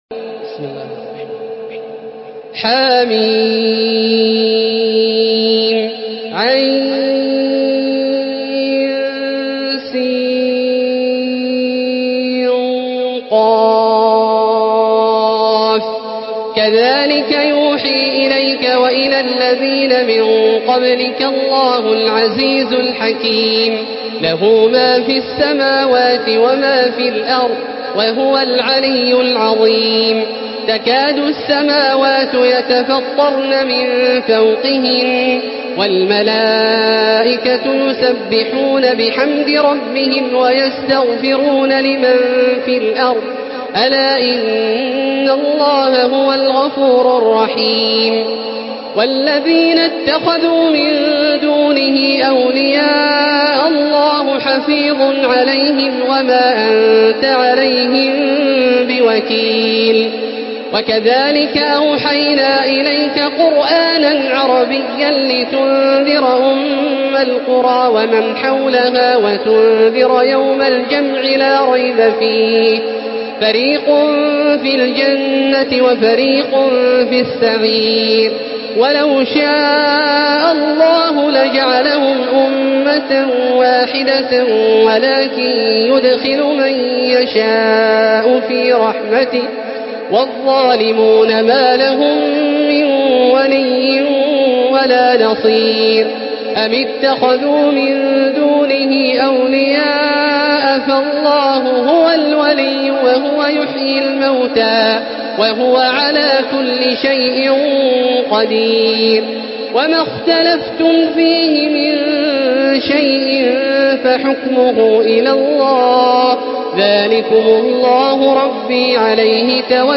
Surah Ash-Shura MP3 by Makkah Taraweeh 1435 in Hafs An Asim narration.
Murattal